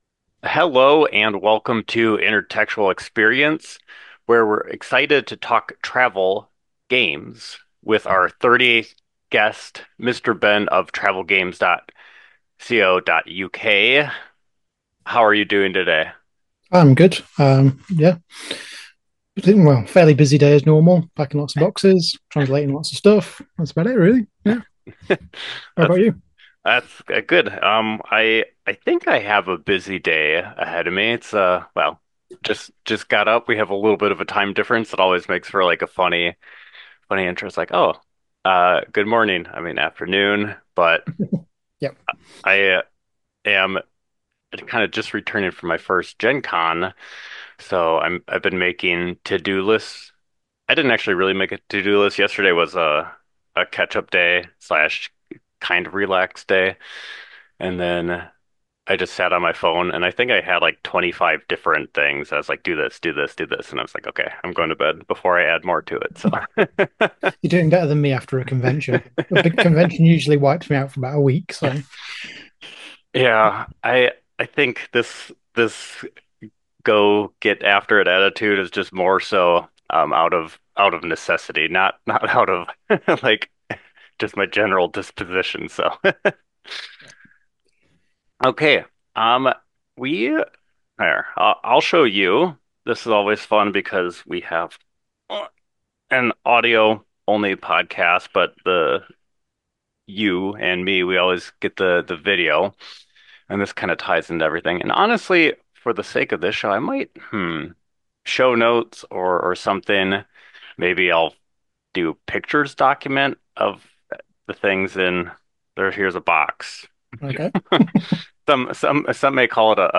A primarily interview-based podcast grounded in the board game hobby. By exploring the connections between board games and other mediums, Intertextual Experience is looking to tap into some links that may not have been considered much previously.